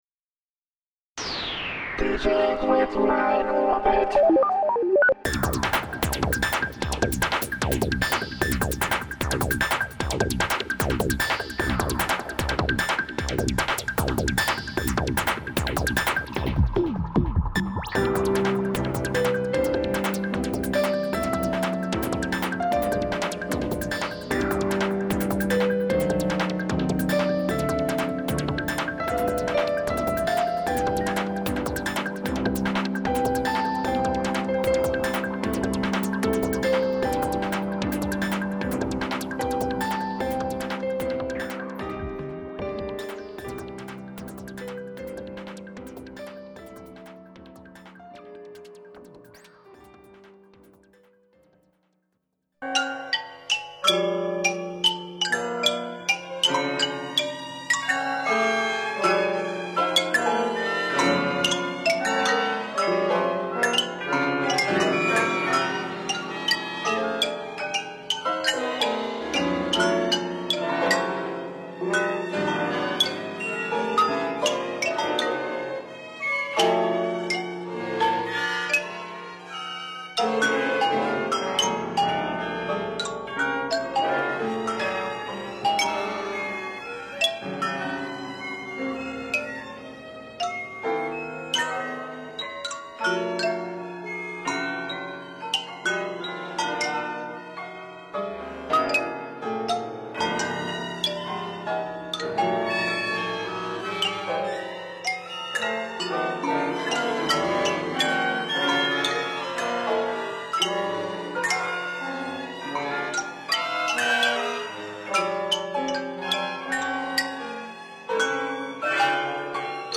esquisses japonaises pour piano solo et petit orchestre